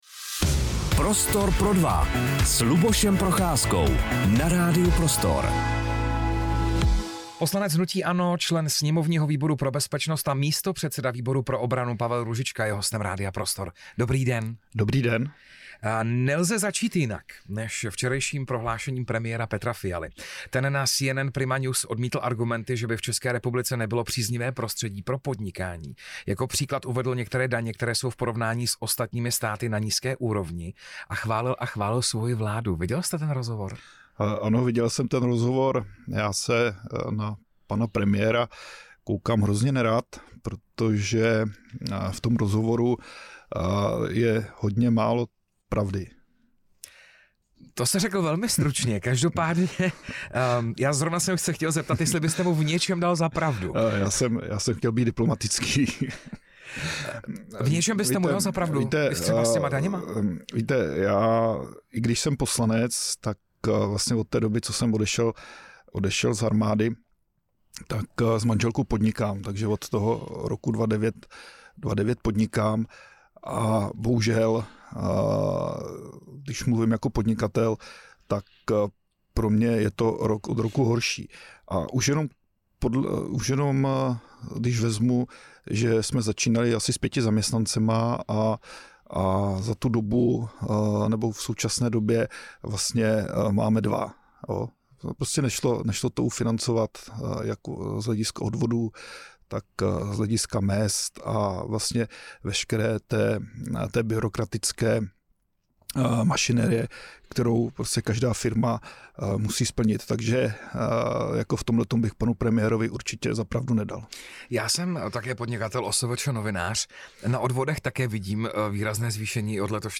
Přestože téma obrany může znít těžkopádně, Růžičkovy odpovědi byly někdy vážné, jindy odlehčené, ale trefné.